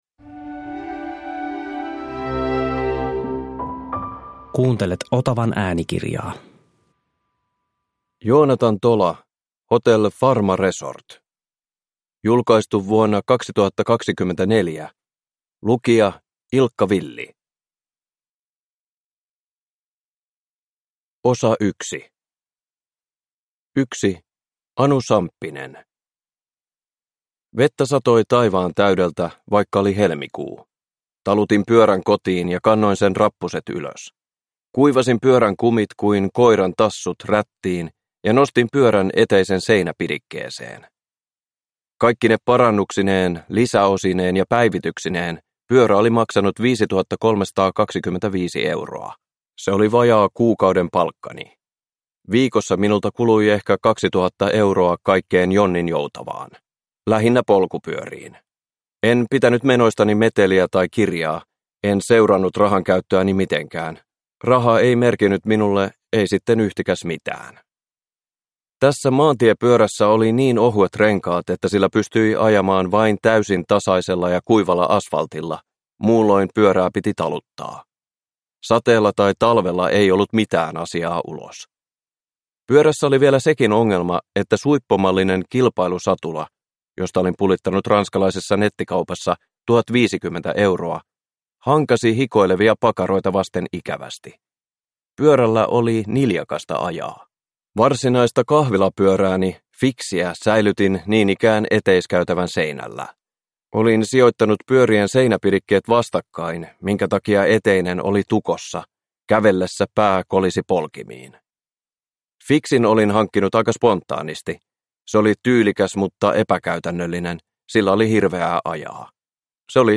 Uppläsare: Ilkka Villi
• Ljudbok